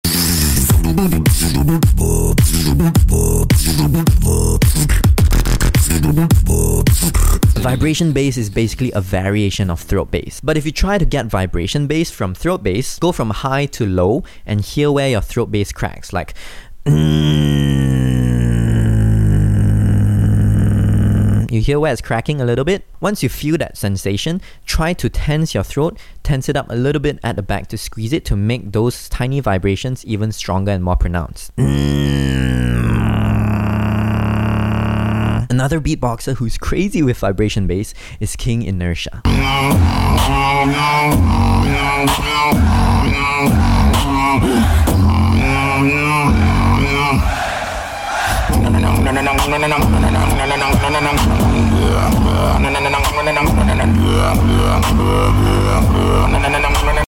Vibration BASS 👹🫨 Mini Sound Effects Free Download
Vibration BASS 👹🫨 - mini